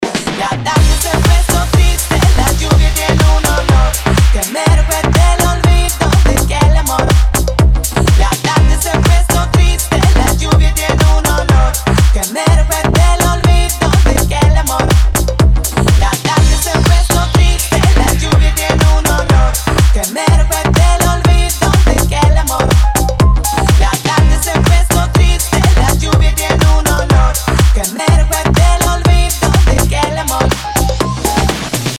• Качество: 320, Stereo
Club House